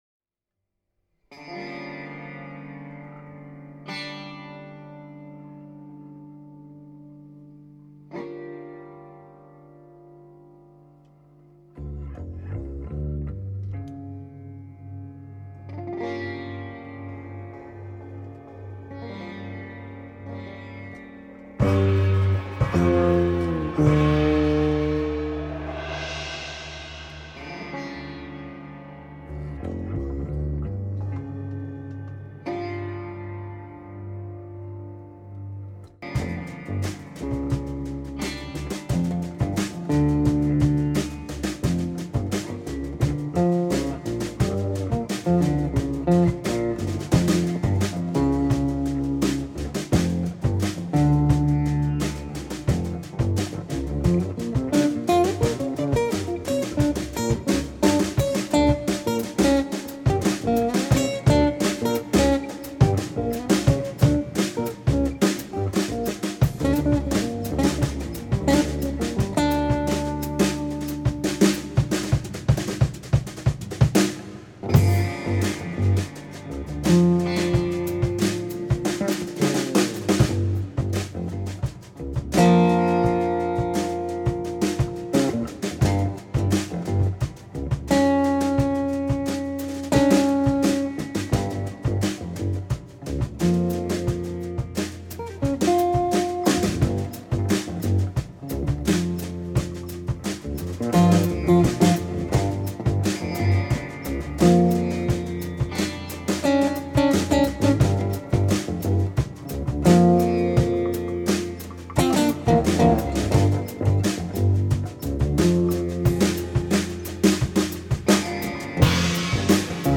Guitare – St Guitare
Basse
Batterie